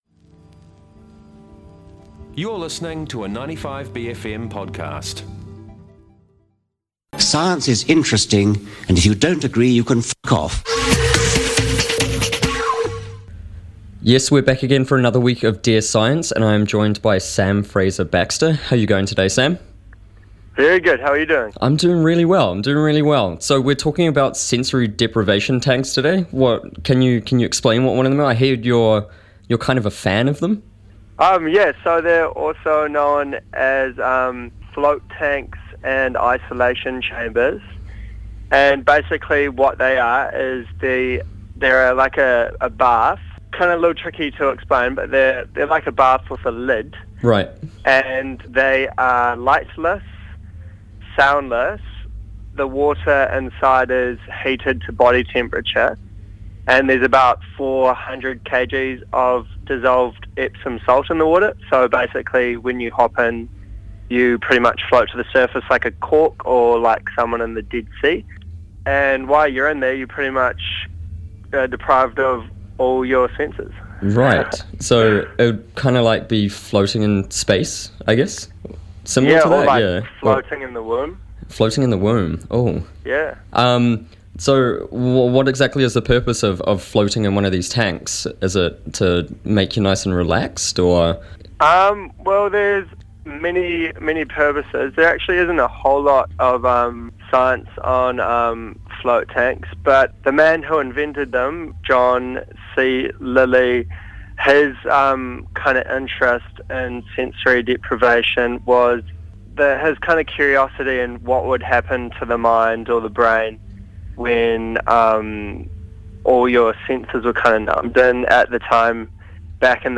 International Desk, our segment on underreported and breaking news from around the globe.